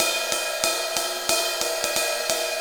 Ride Cymbal Pattern 06.wav